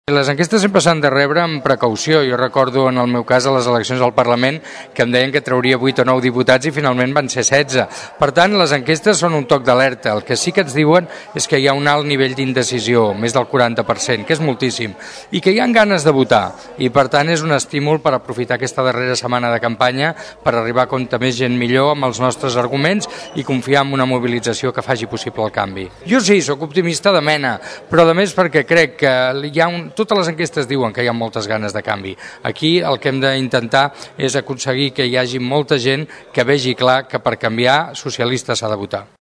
Prèviament, en declaracions en aquesta emissora, Iceta va demanar el vot pels socialistes assegurant que és l’única manera que hi hagi un canvi.